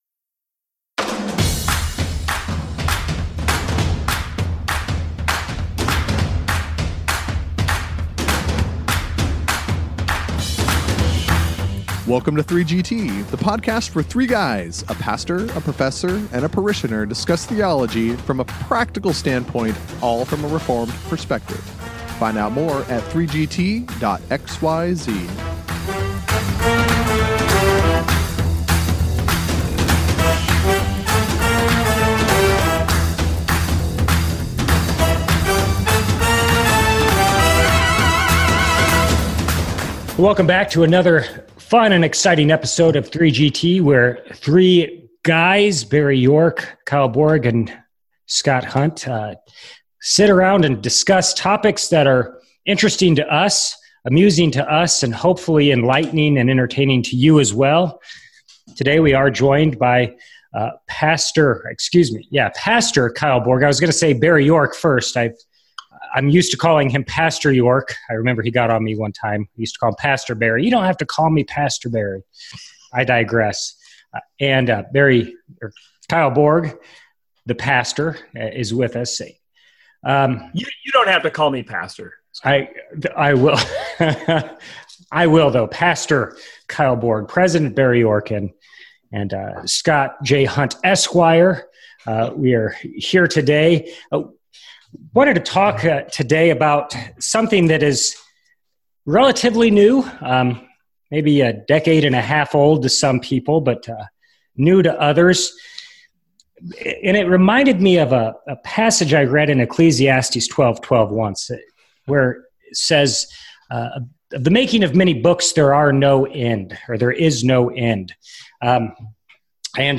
Tune into this episode of 3GT and enjoy this lively discussion!